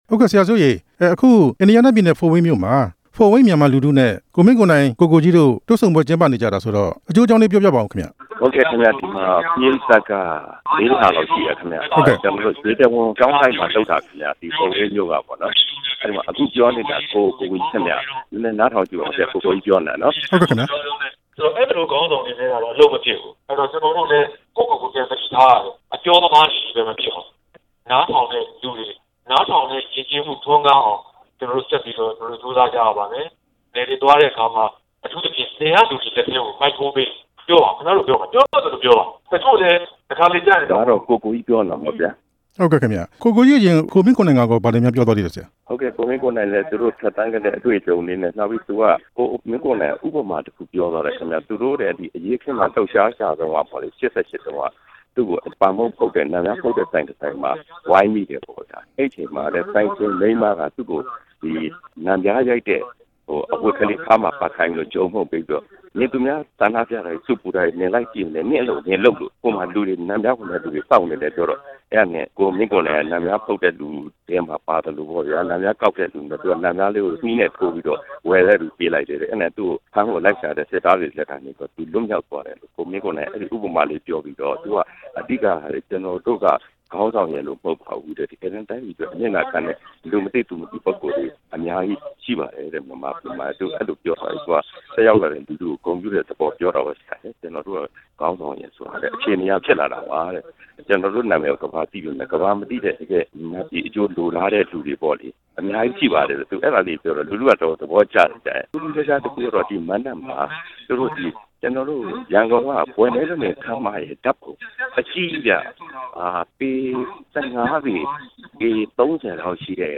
ဖို့ဝိန်းမြို့ မြန်မာမိသားစုတွေနဲ့ တွေ့ဆုံပွဲ မေးမြန်းချက်